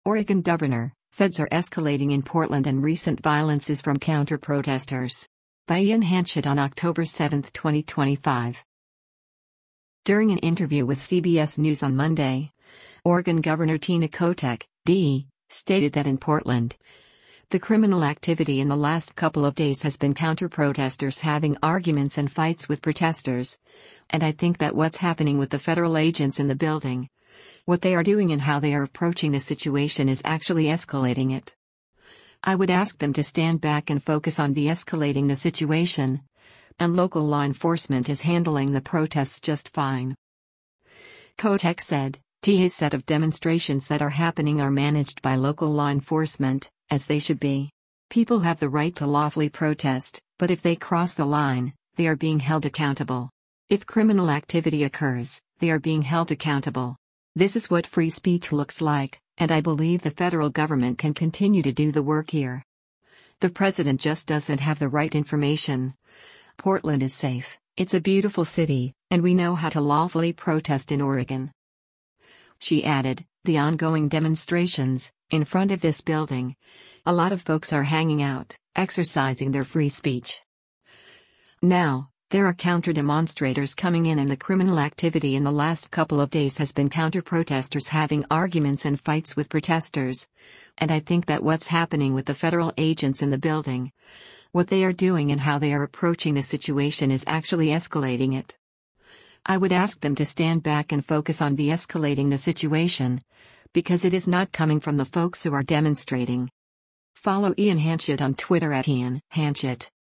During an interview with CBS News on Monday, Oregon Gov. Tina Kotek (D) stated that in Portland, “the criminal activity in the last couple of days has been counterprotesters having arguments and fights with protesters, and I think that what’s happening with the federal agents in the building, what they are doing and how they are approaching the situation is actually escalating it.